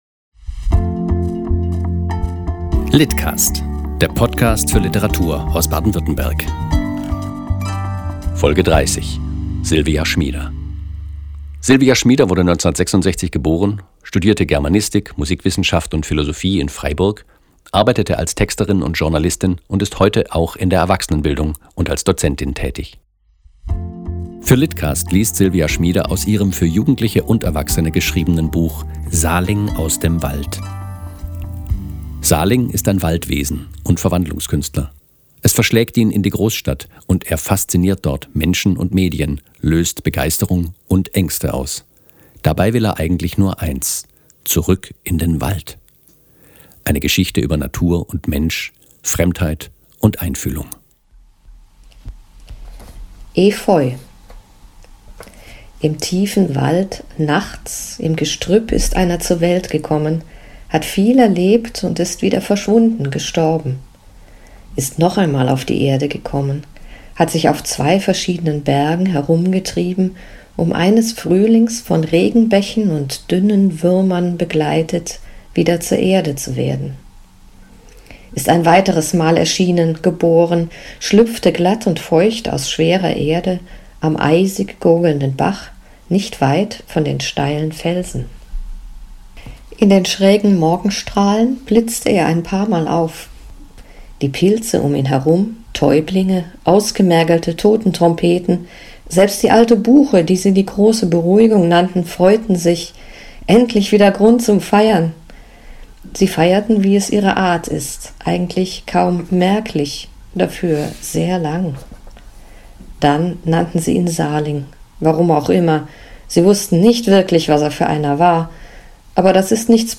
liest aus "Salings Reise zurück in den Wald"